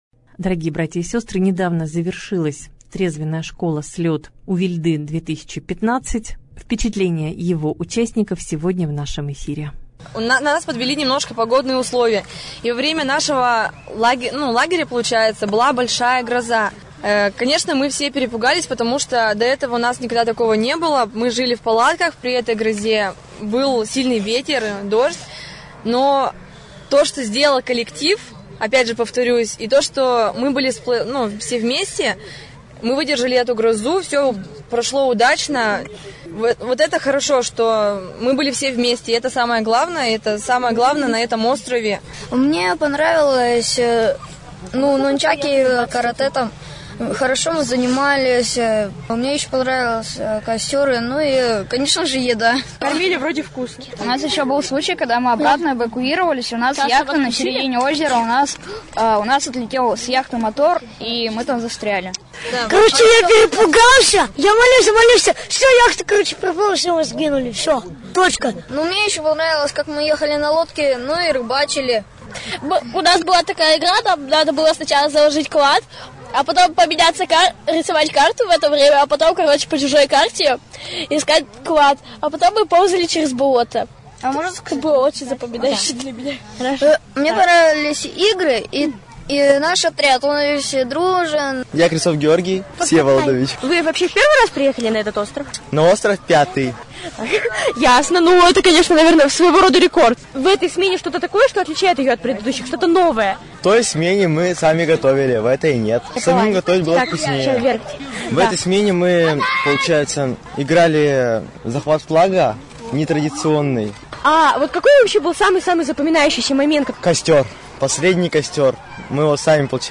Репортаж дня